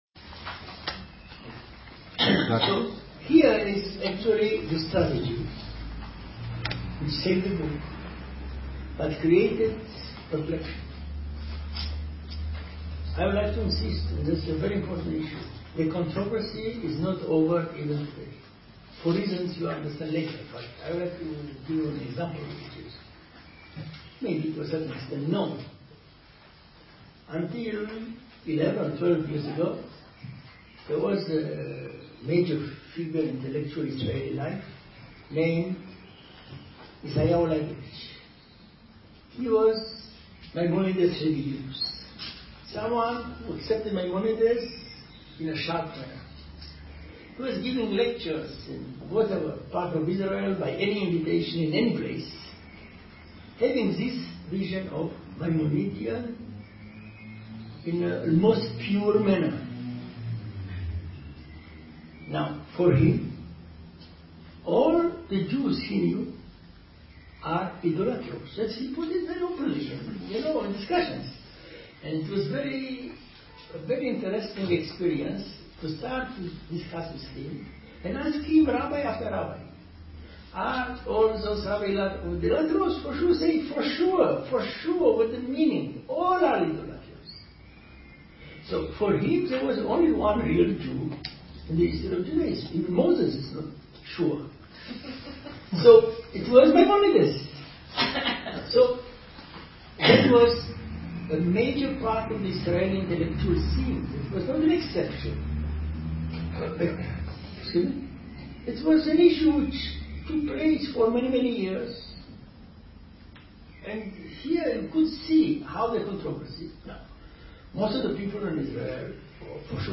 Seminario Moshé Idél - primo file